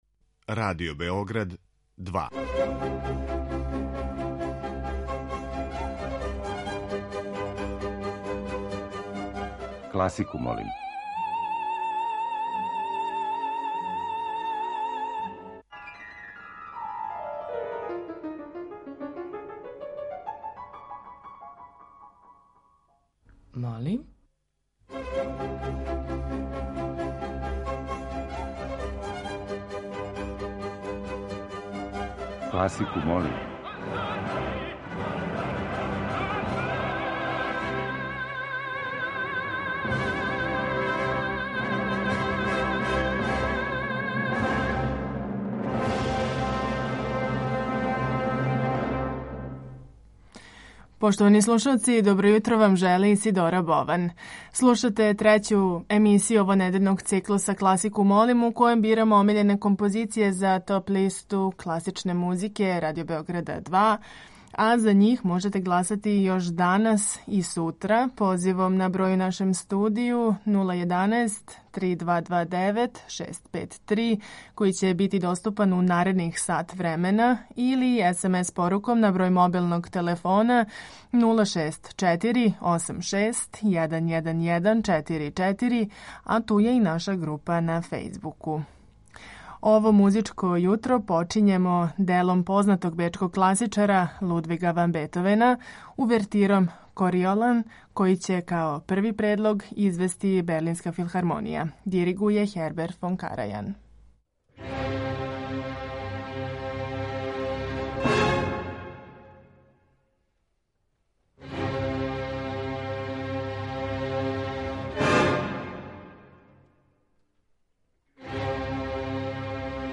Тема циклуса носи наслов 'Концерти за виолу'.